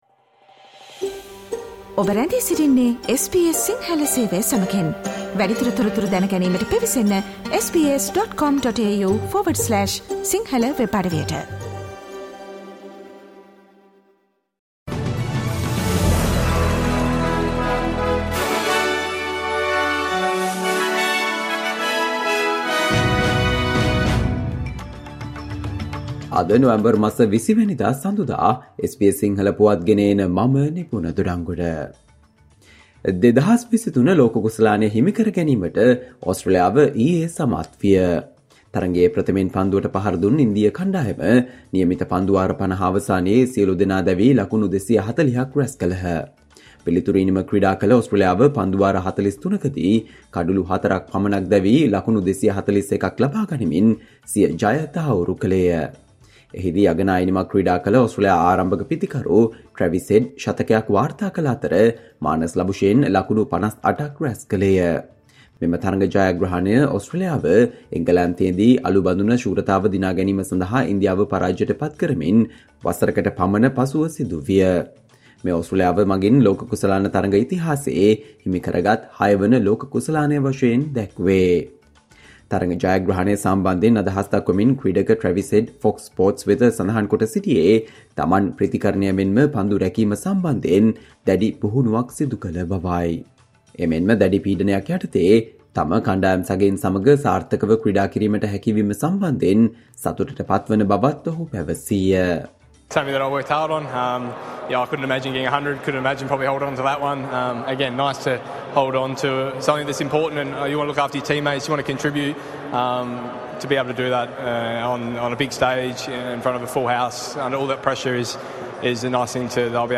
Australia news in Sinhala, foreign and sports news in brief - listen, Monday 20 November 2023 SBS Sinhala Radio News Flash